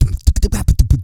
PARADIDDLY.wav